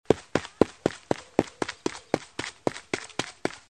Звуки бегущего человека
Шум стремительного бега